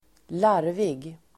Ladda ner uttalet
larvig adjektiv (vardagligt), silly [informal]Uttal: [²l'ar:vig] Böjningar: larvigt, larvigaSynonymer: fjantig, fånig, löjligDefinition: dum, fånig